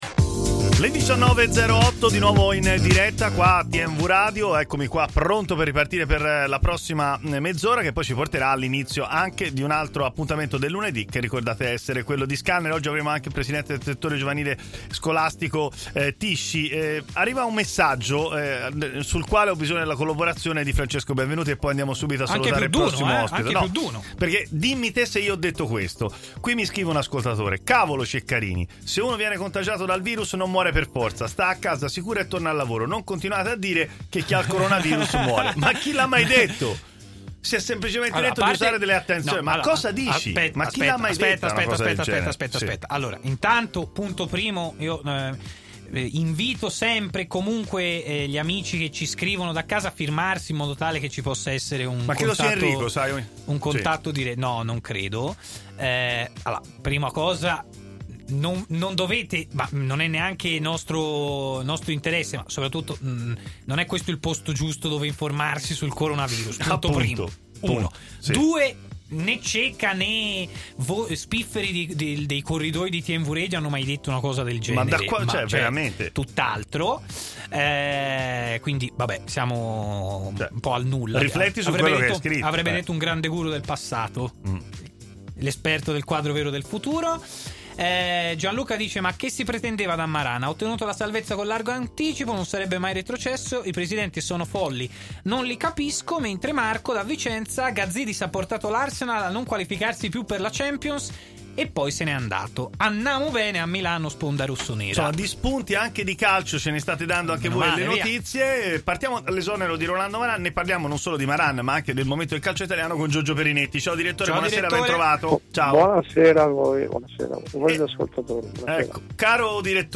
ai microfoni di “Stadio Aperto” su TMW Radio commenta l’esonero di Maran, la situazione societaria del Milan e la gestione dell’emergenza del Coronavirus.